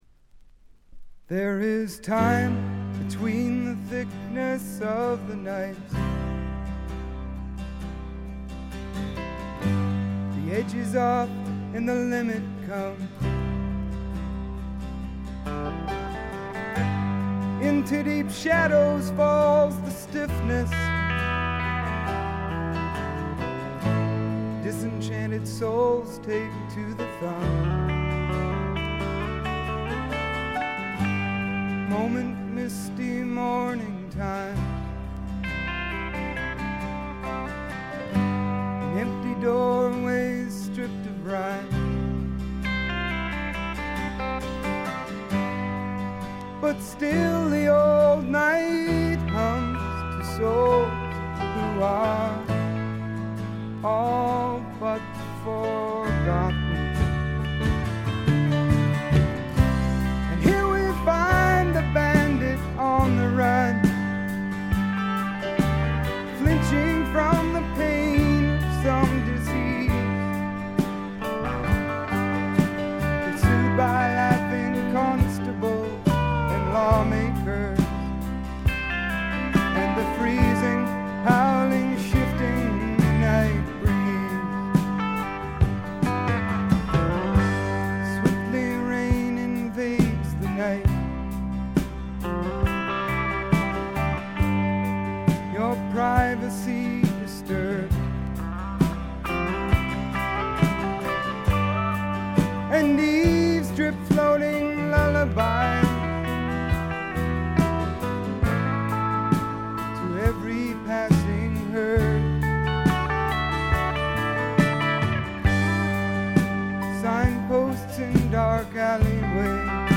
ところどころでチリプチ。
曲が素晴らしくよくできていてバックの演奏もシンプルで実に的確。
試聴曲は現品からの取り込み音源です。
Vocals, Acoustic Guitar